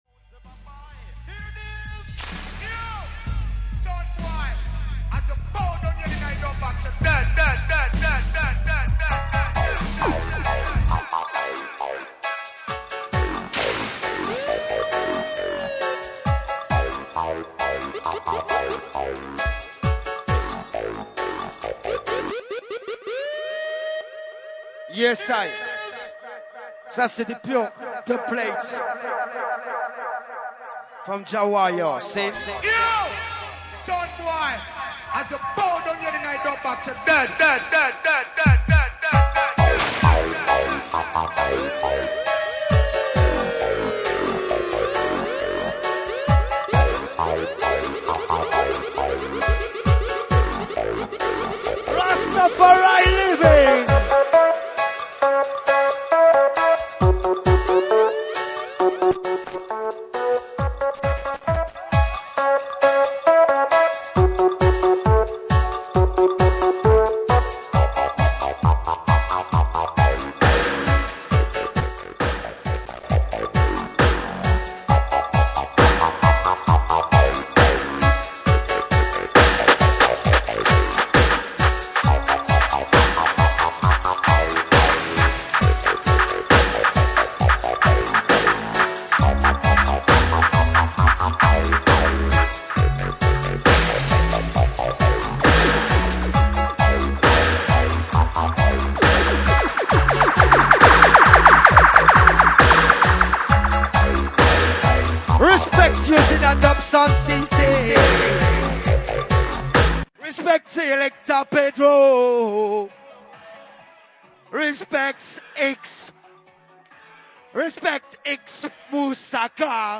on Monte le Son dans la rue; la fête de la zik,
beaucoup de passage et chaude ambiance autour
du sound system, le son est Lourd n'Loud!
live&direct